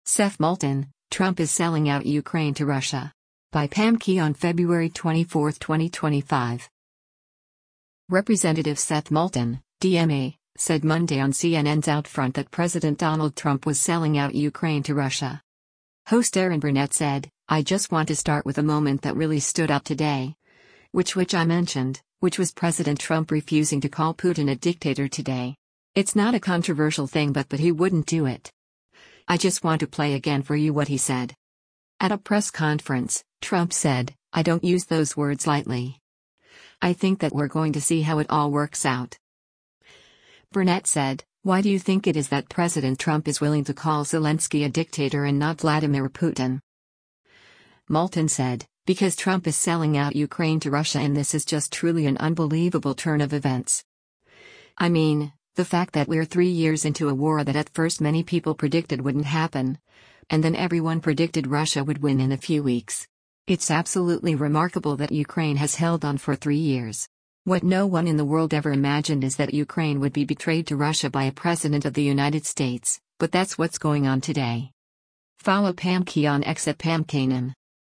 Representative Seth Moulton (D-MA) said Monday on CNN’s “OutFront” that President Donald Trump was “selling out Ukraine to Russia.”
At a press conference, Trump said, “I don’t use those words lightly. I think that we’re going to see how it all works out.”